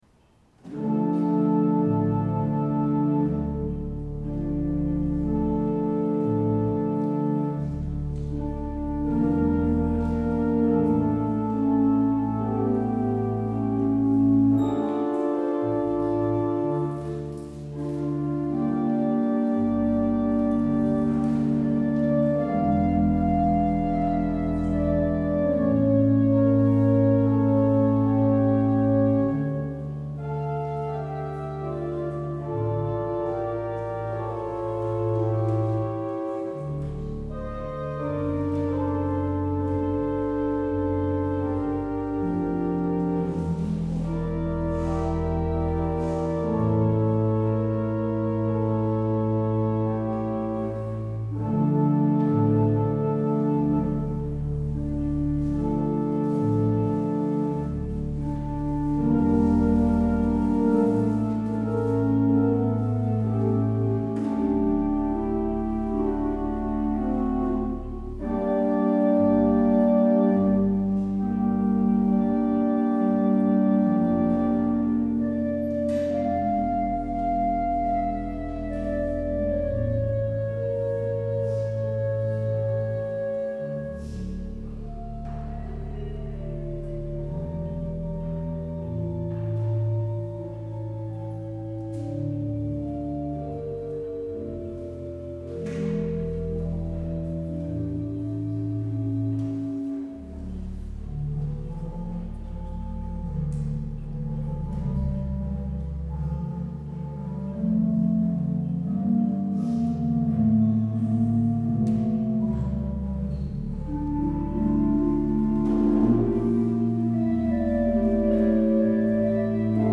Het openingslied is: Psalm 87: 1-4.